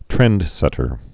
(trĕndsĕtər)